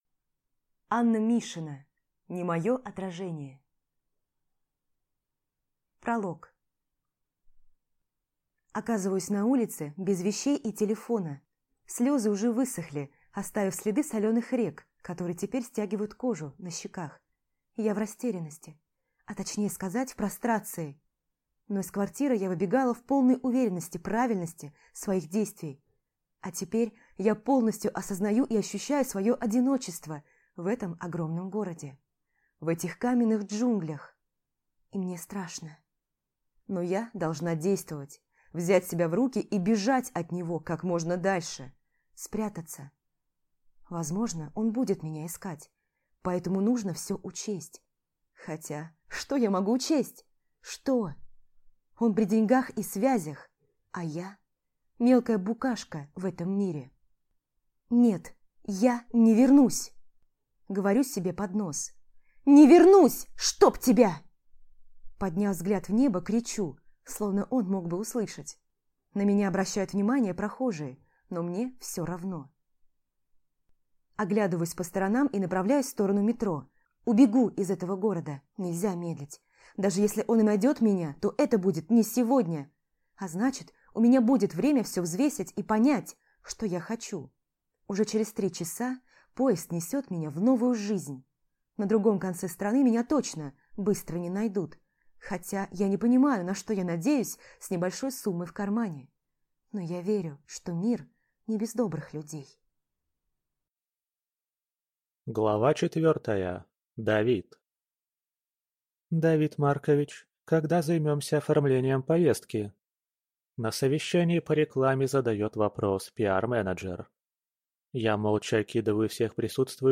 Аудиокнига Не мое отражение | Библиотека аудиокниг